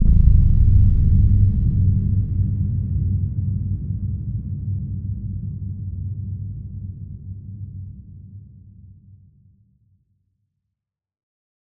Low End 21.wav